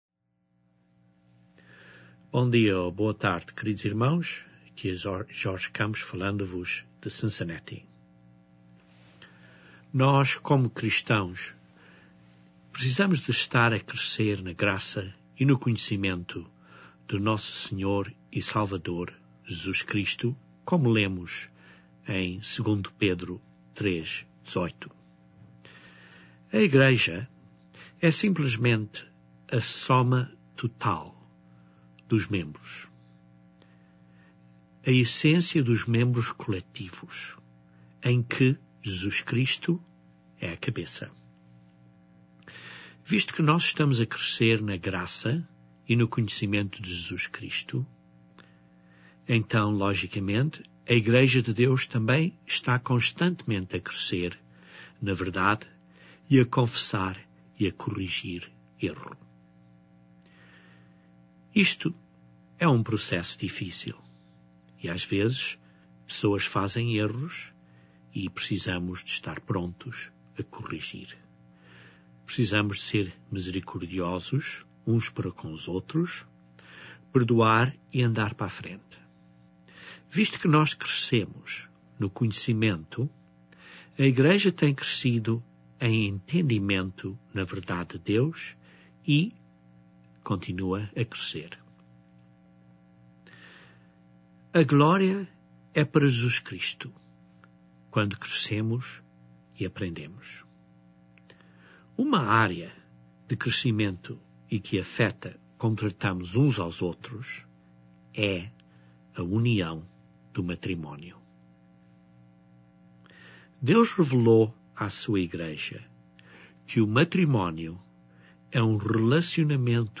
O matrimónio é um relacionamento ao nível de Deus, que não é oferecida a qualquer outra forma de vida criada por Deus. Este sermão descreve a criação do matrimónio e as leis e instruções de Deus acerca do matrimónio.